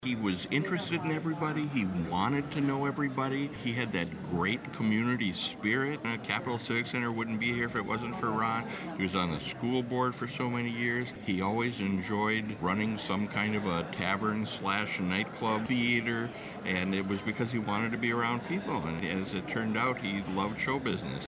During a formal program one speaker after another recalled the father, grandfather and friend with serious and funny anecdotes.